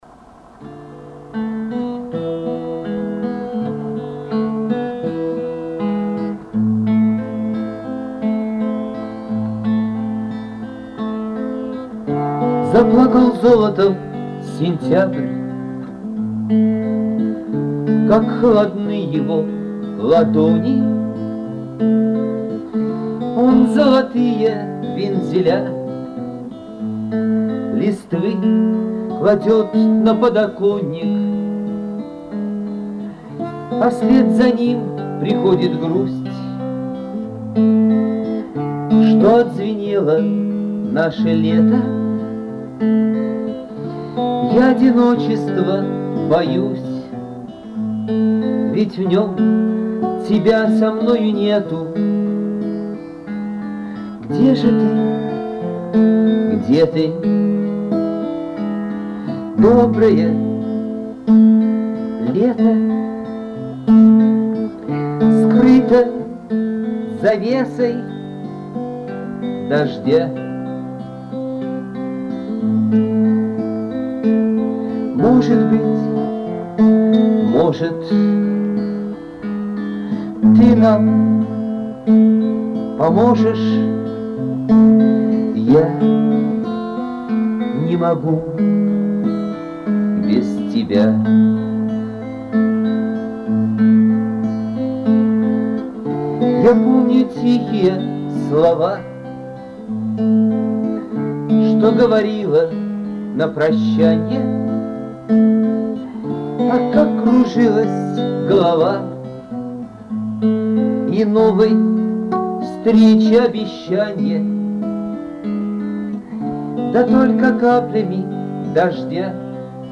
Уважаемые посетители и участники, нет ли у кого ни будь из Вас возможности убрать лишние шумы у этого трека, который переписали в цифровой вариант с записи на кассетном магнитофоне ?
Часть шумов можно убрать, но исходник всё равно низкого качества, так что чудес не ждите...